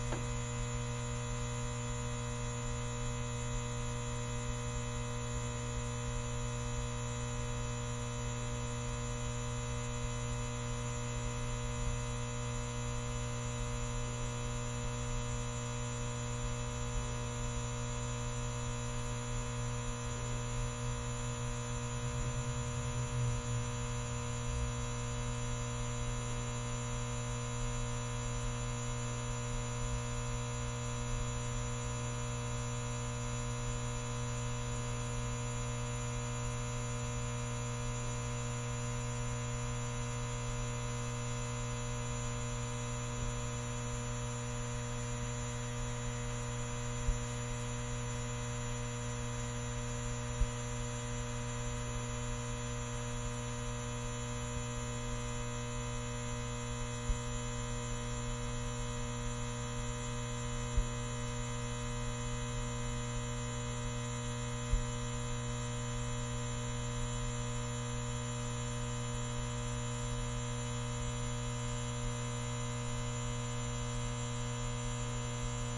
随机" 霓虹灯嗡嗡嗡立体声接近低切的味道9
描述：霓虹灯嗡嗡声嗡嗡声立体声关闭lowcut to taste9.flac